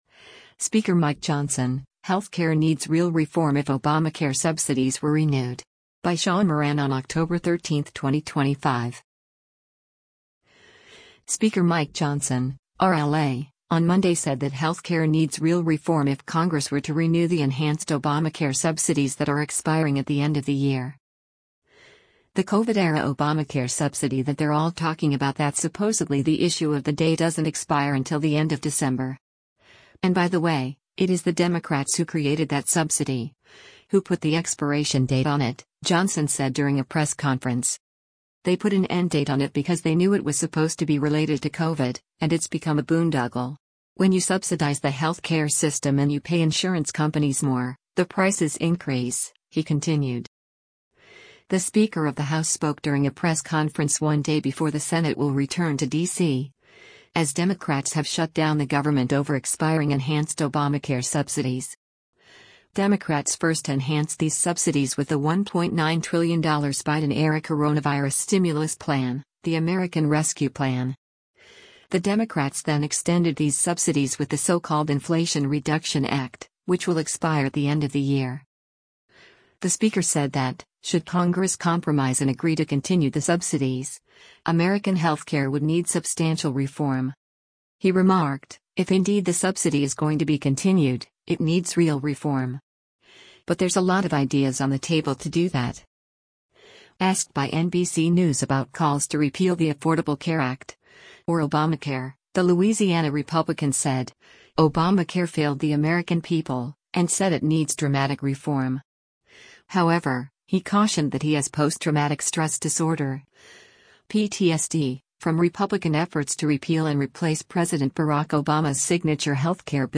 The Speaker of the House spoke during a press conference one day before the Senate will return to D.C., as Democrats have shut down the government over expiring enhanced Obamacare subsidies.